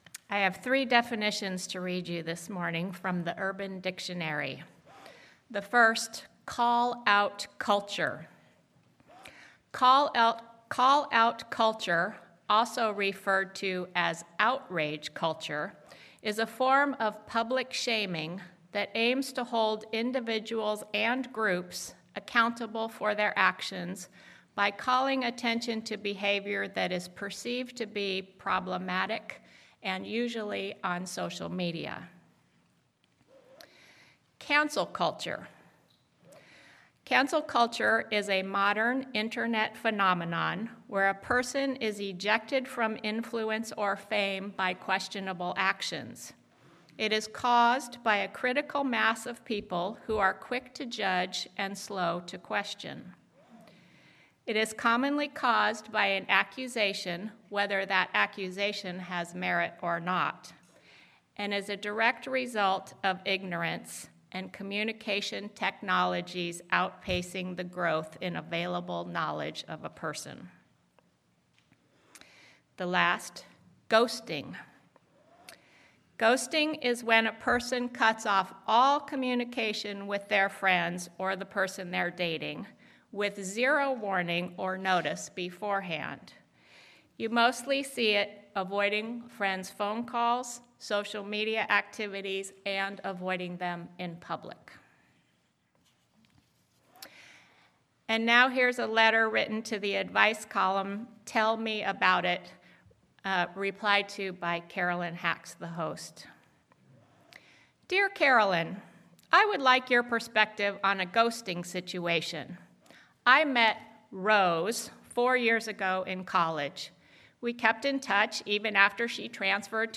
Sermon-Cancel-Culture.mp3